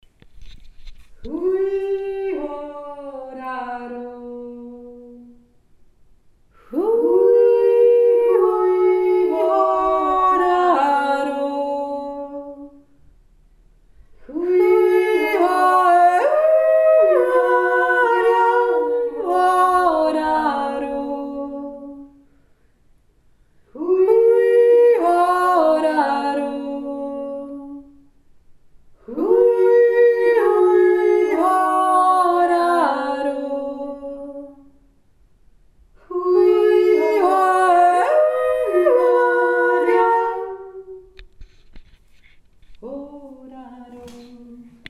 2 Stimmen gemeinsam
hui-hodaro-zweistimmen.mp3